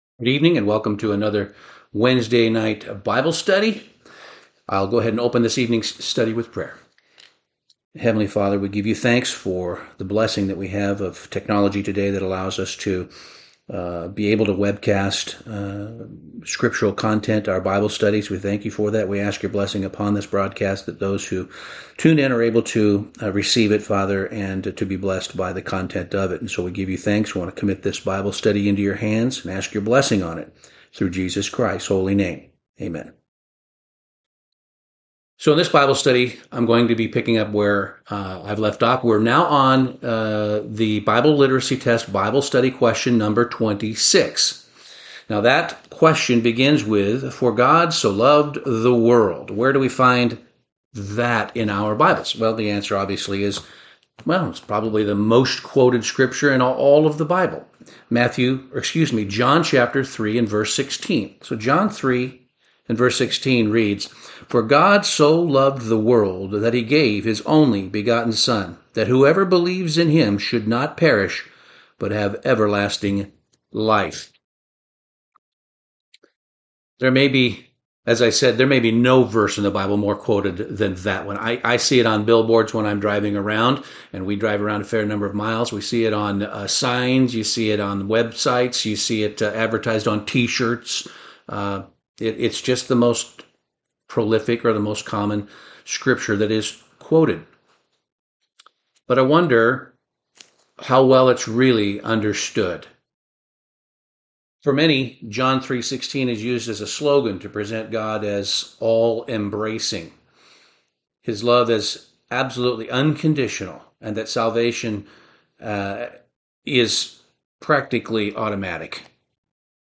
NW Bible Study - Bible Quiz #26 - God So Loved the World